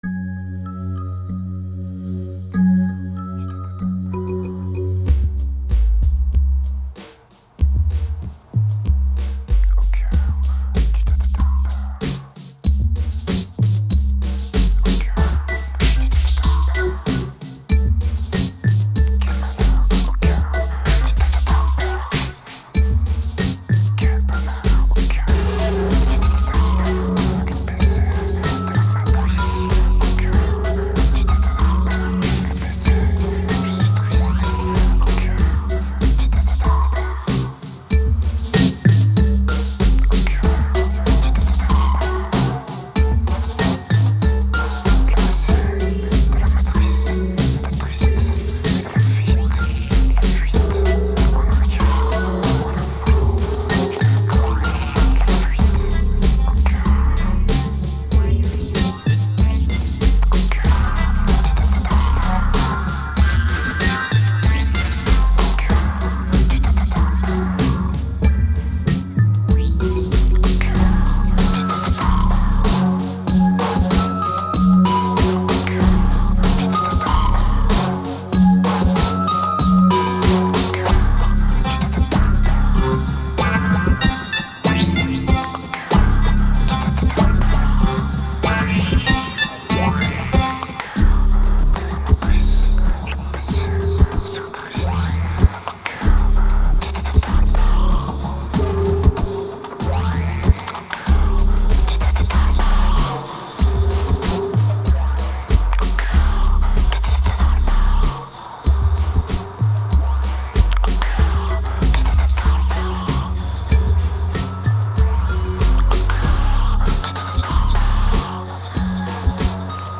2102📈 - -63%🤔 - 95BPM🔊 - 2008-10-17📅 - -414🌟